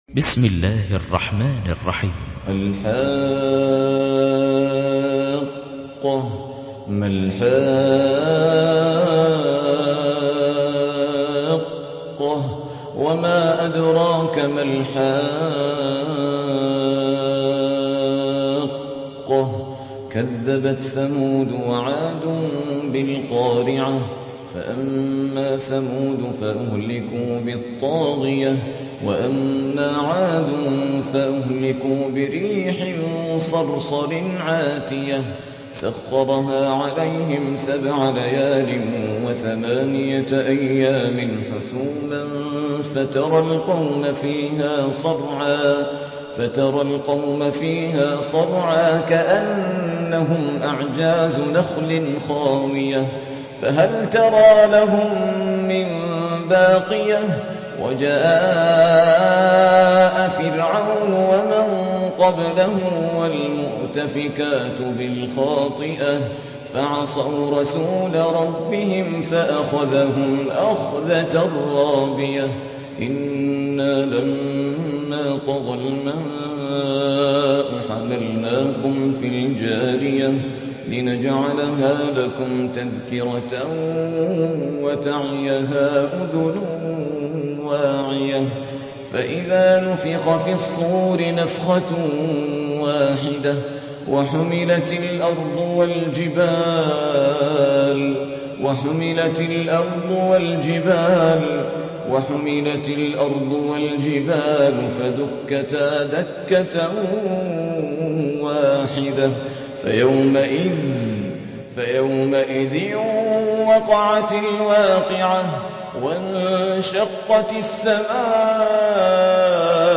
مرتل